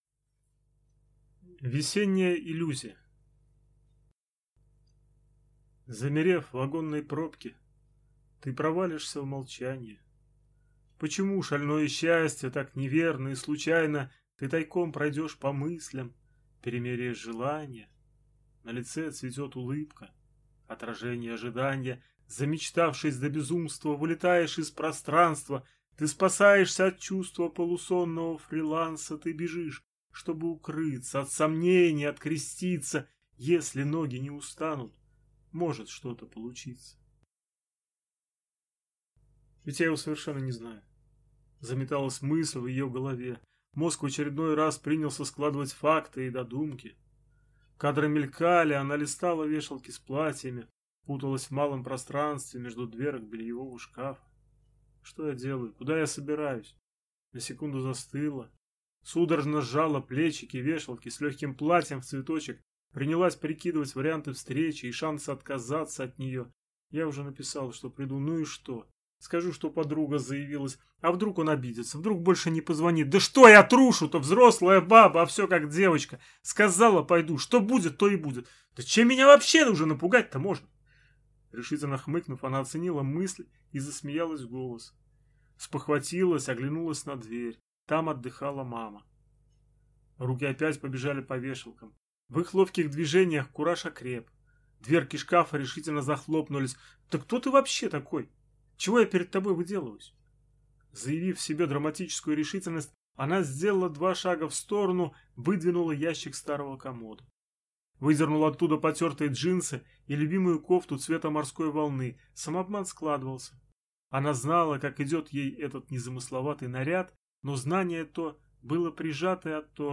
Аудиокнига Хрупкие слова женского рода | Библиотека аудиокниг